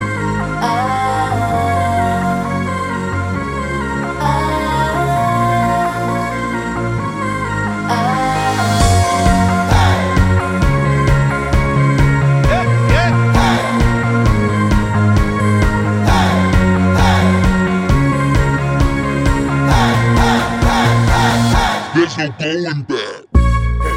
Duet Version Pop (2010s) 3:32 Buy £1.50